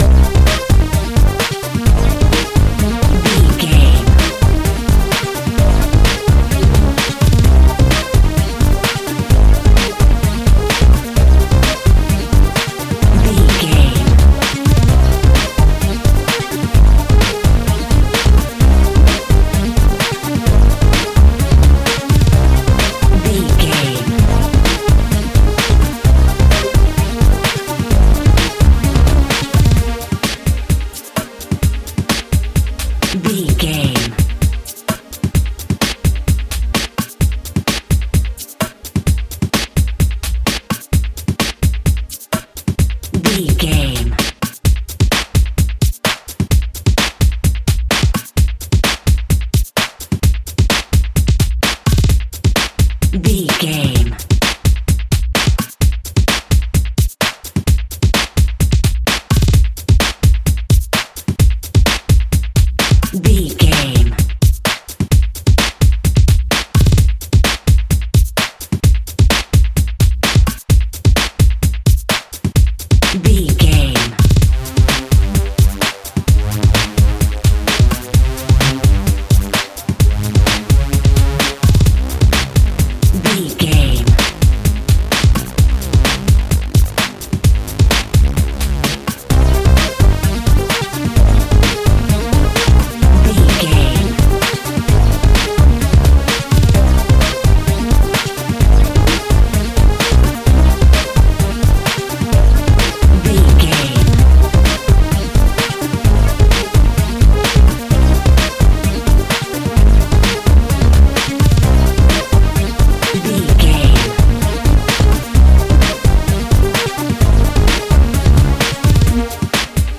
Electro Pop Fitness Workout.
Ionian/Major
Fast
breakbeat
energetic
synth pop
uplifting
powerful
drums
synth bass
percussion
synth lead